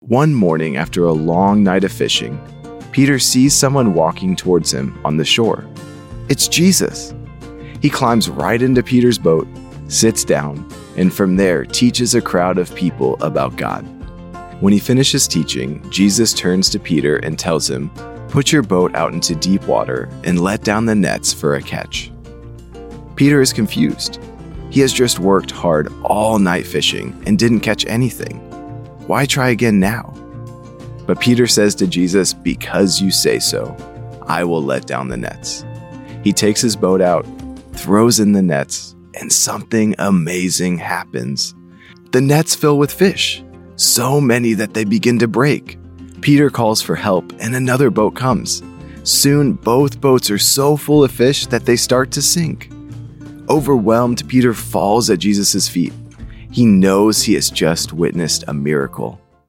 Kids Audio Bible Stories